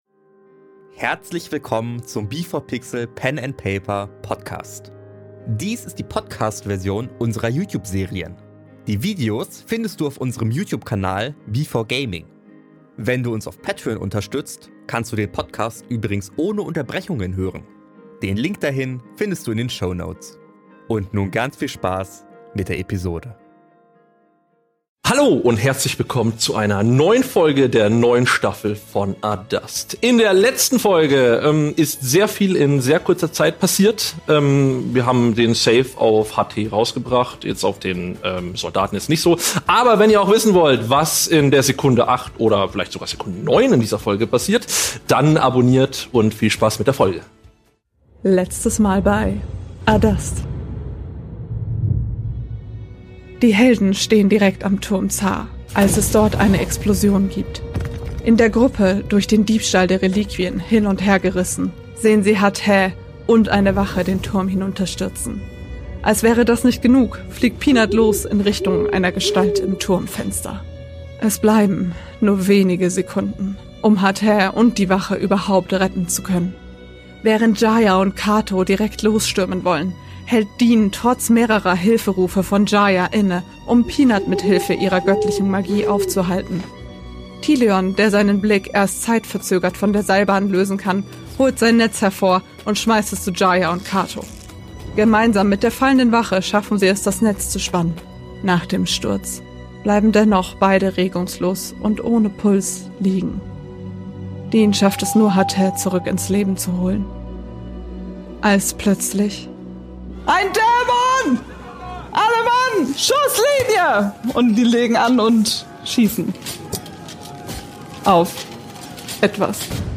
Wir spielen auf dem YouTube B4Gaming regelmäßig Pen and Paper und veröffentlichen dort unsere Serien. Dies hier ist die Podcast-Version mit Unterbrechungen.